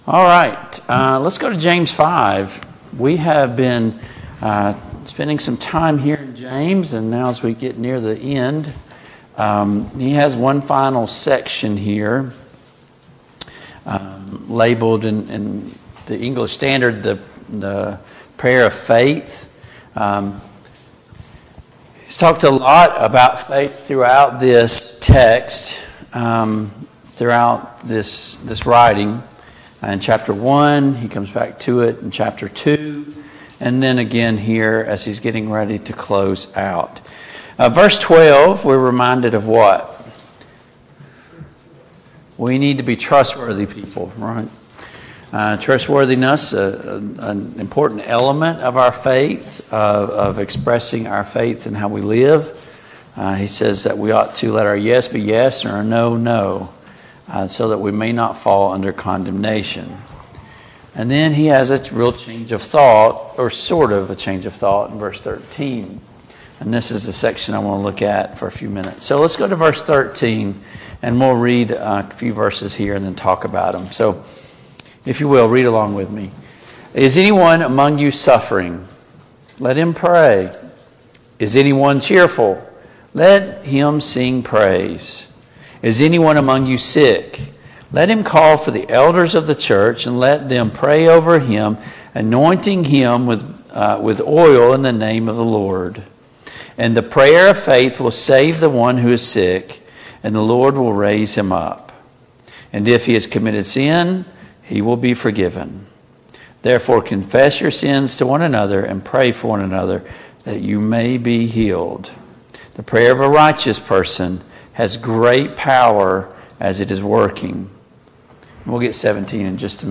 James 5:13-20 Service Type: Family Bible Hour Topics